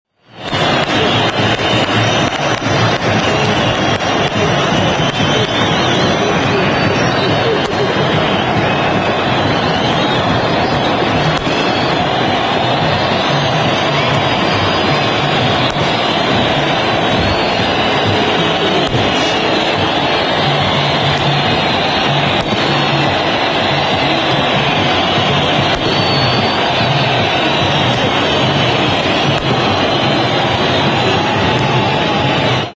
As the celebration continues in Egypt, we round up the best sights and sounds from the historic day.